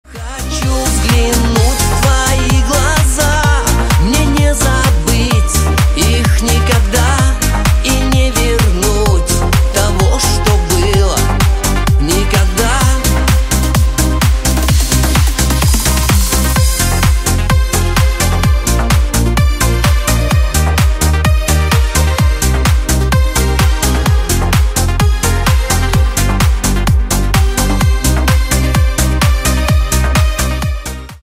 Шансон
Скачать припев песни